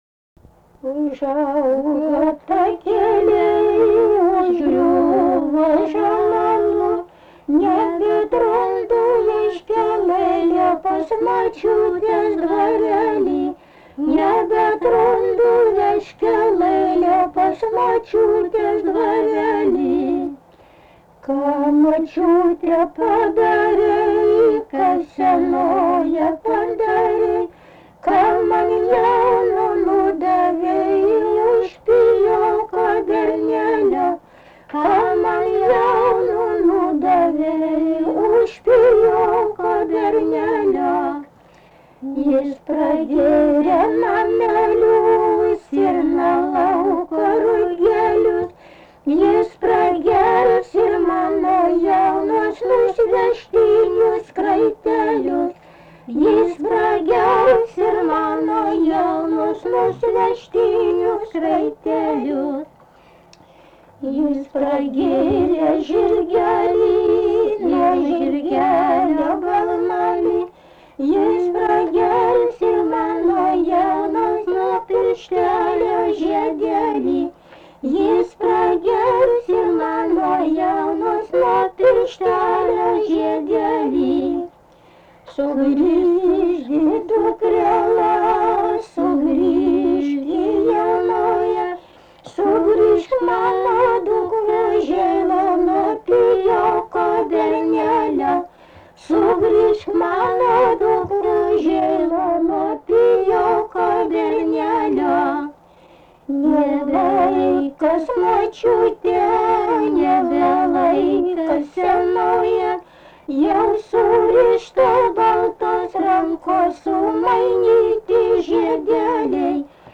Subject daina
Erdvinė aprėptis Anykščiai
Atlikimo pubūdis vokalinis